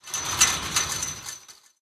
ambienturban_1.ogg